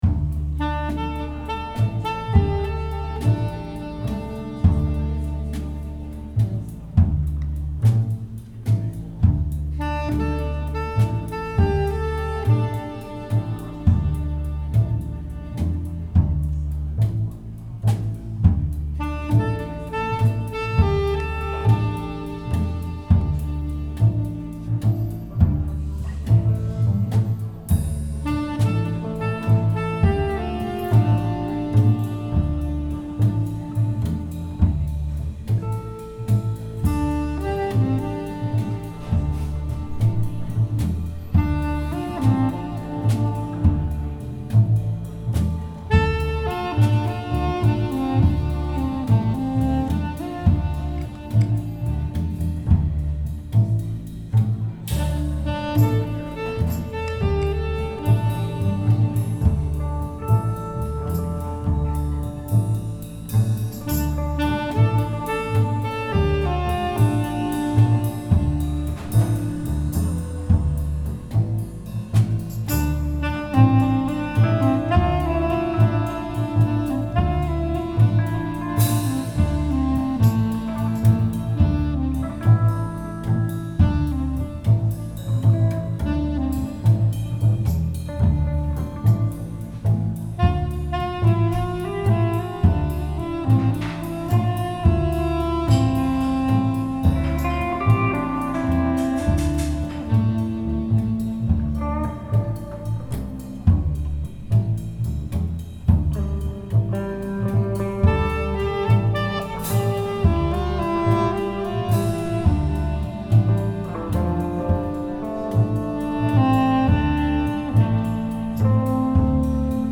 songs recorded at the café in the last few years